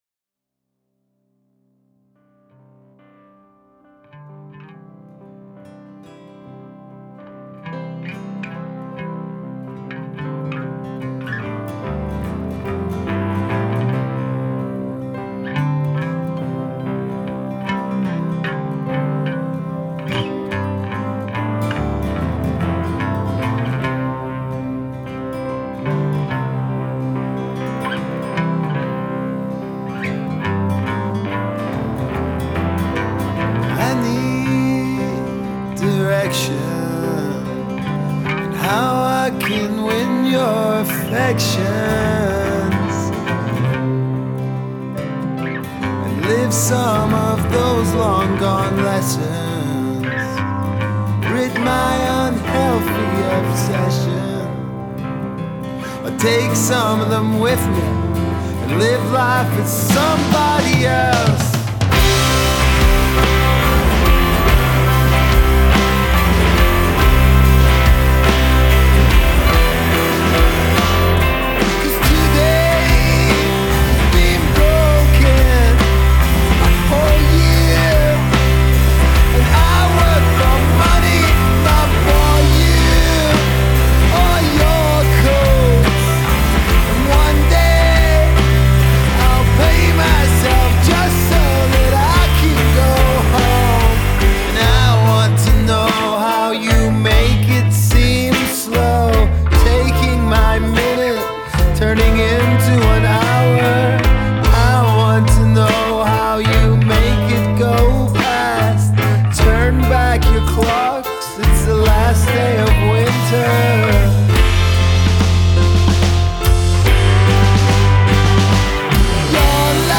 Genre: acoustic, experimental, folk rock